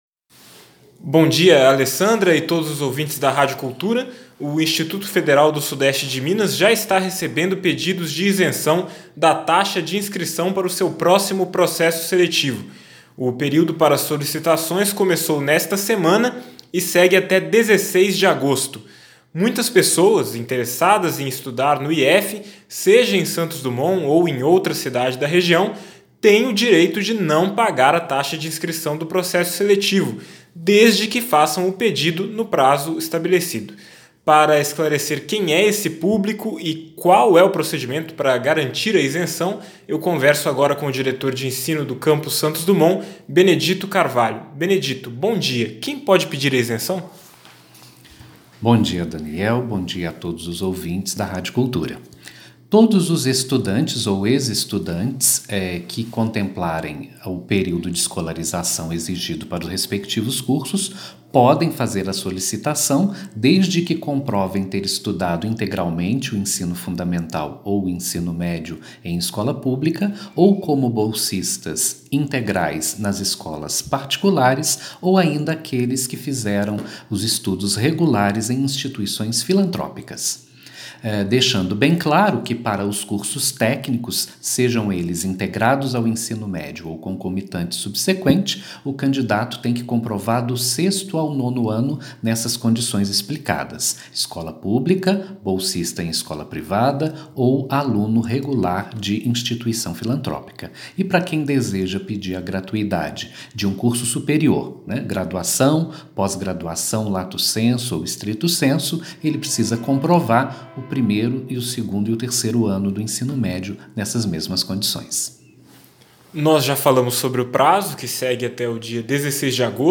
Entrevista sobre período para pedidos de isenção.mp3